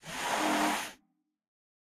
Minecraft Version Minecraft Version 25w18a Latest Release | Latest Snapshot 25w18a / assets / minecraft / sounds / mob / sniffer / sniffing2.ogg Compare With Compare With Latest Release | Latest Snapshot
sniffing2.ogg